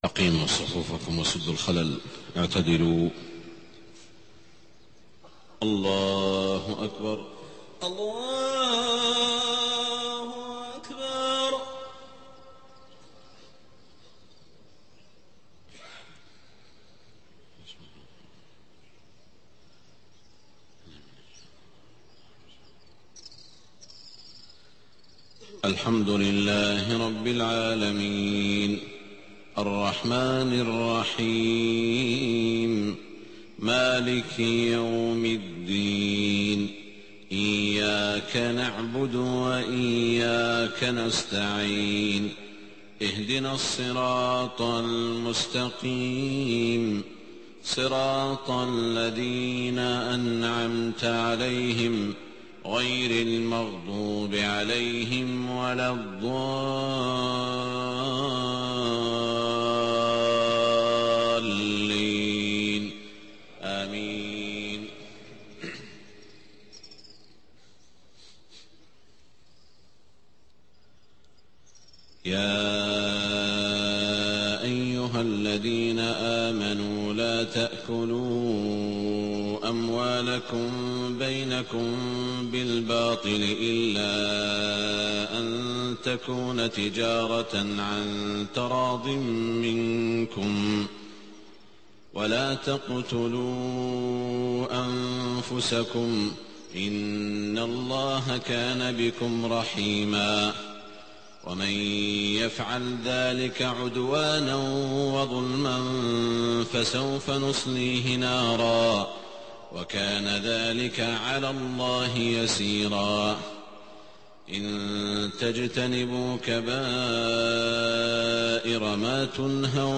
صلاة الفجر 2-2-1428هـ من سورة النساء 29-42 > 1428 🕋 > الفروض - تلاوات الحرمين